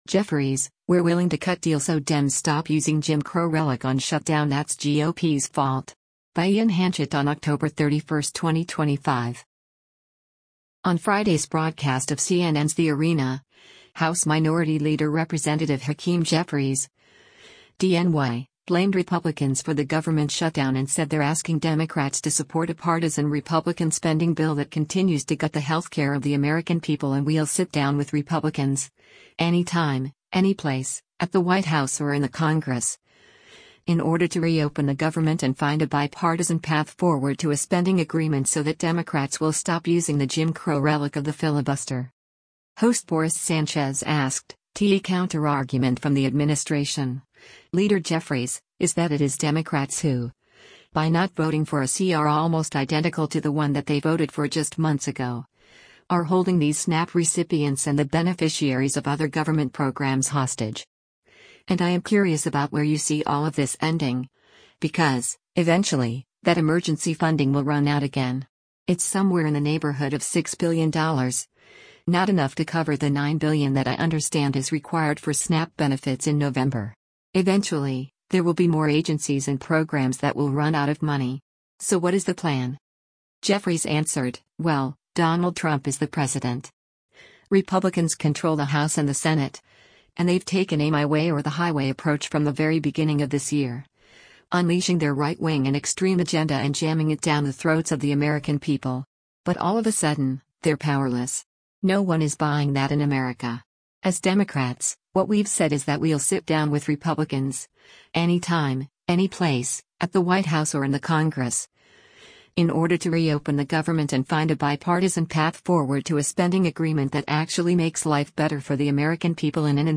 On Friday’s broadcast of CNN’s “The Arena,” House Minority Leader Rep. Hakeem Jeffries (D-NY) blamed Republicans for the government shutdown and said they’re “asking Democrats to support a partisan Republican spending bill that continues to gut the health care of the American people” and “we’ll sit down with Republicans, any time, any place, at the White House or in the Congress, in order to reopen the government and find a bipartisan path forward to a spending agreement” so that Democrats will stop using the “Jim Crow relic” of the filibuster.